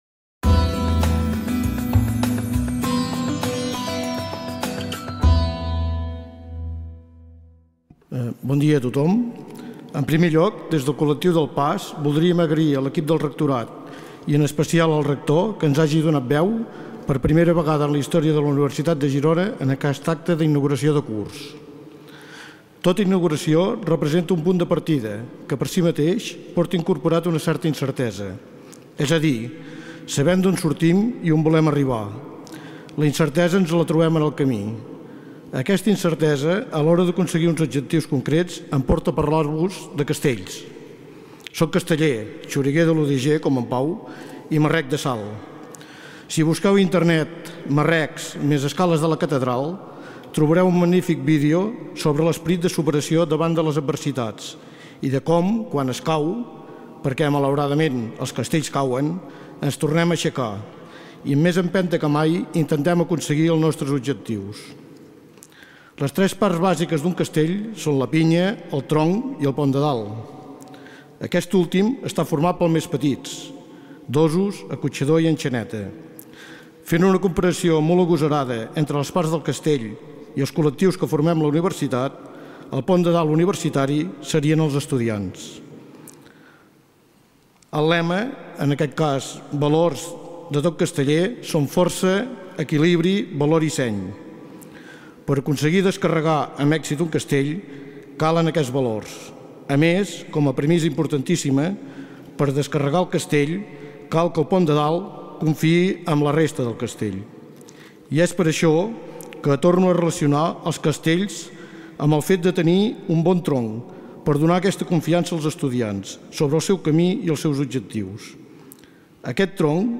Inauguració del curs 2014-2015. Parlament